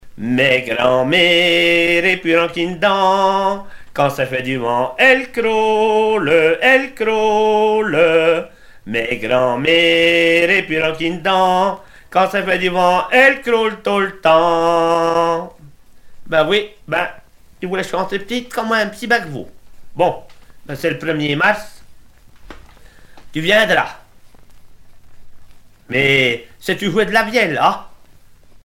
Mémoires et Patrimoines vivants - RaddO est une base de données d'archives iconographiques et sonores.
Genre strophique
Chanteurs et musiciens de villages en Morvan